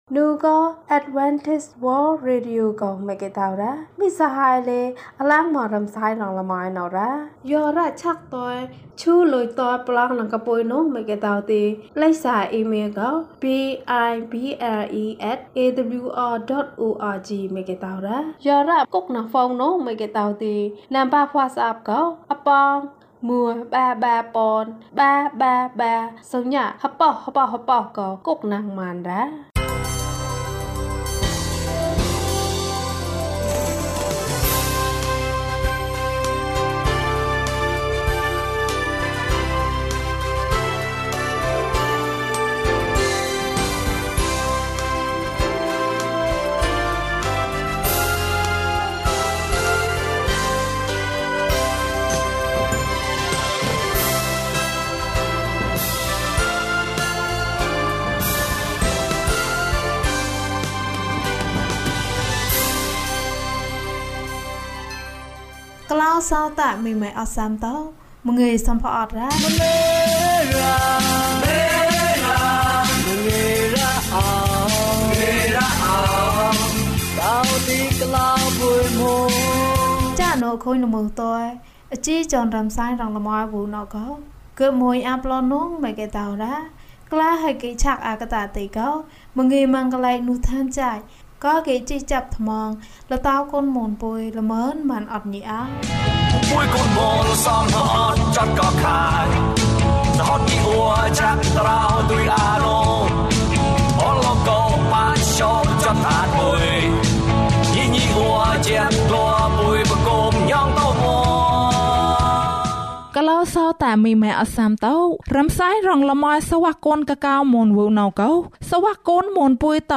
ယေရှုကို သိကျွမ်းခြင်းအတွက် သင့်ကလေးများကို သွန်သင်ပေးပါ။ ကျန်းမာခြင်းအကြောင်းအရာ။ ဓမ္မသီချင်း။ တရားဒေသနာ။